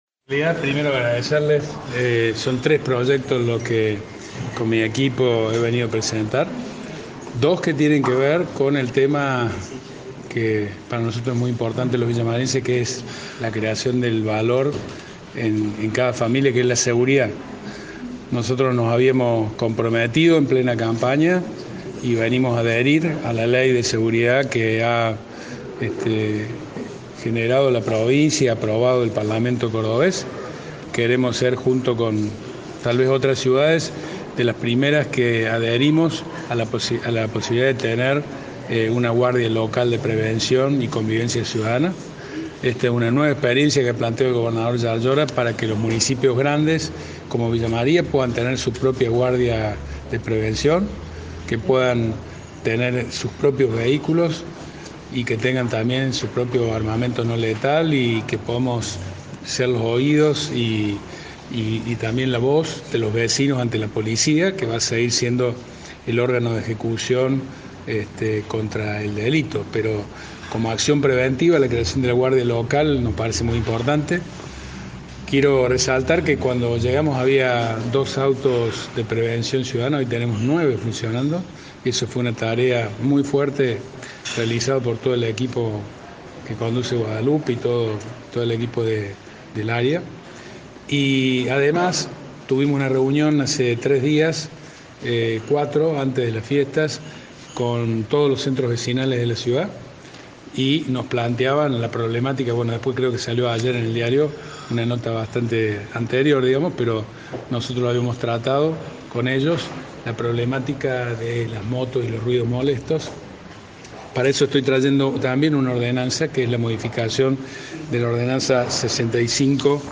Este miércoles ante la prensa, el intendente Eduardo Accastello se refirió a varias temáticas de interés para la ciudad, creación de la guardia local, para mayor seguridad de la población, algo que venían reclamando muchos vecinos.